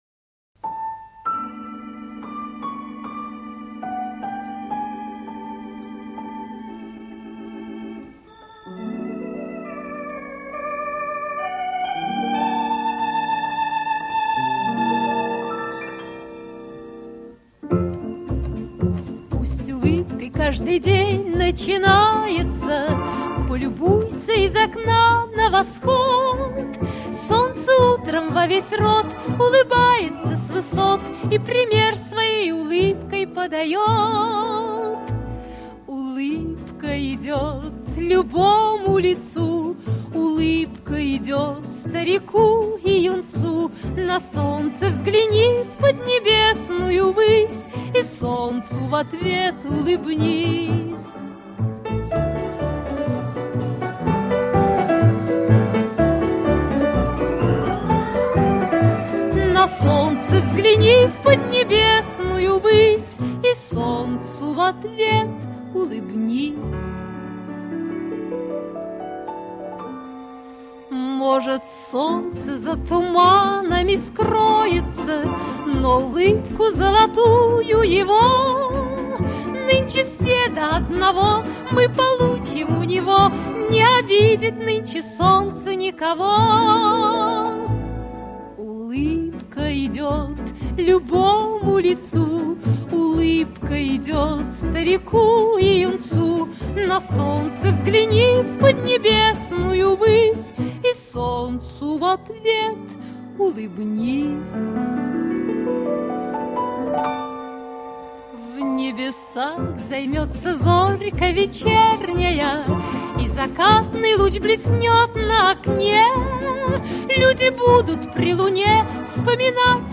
Вот подлинник (без помех).